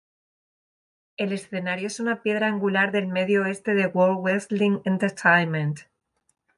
Read more angular Frequency 28k Hyphenated as an‧gu‧lar Pronounced as (IPA) /anɡuˈlaɾ/ Etymology Borrowed from Latin angulāris In summary Borrowed from Latin angulāris.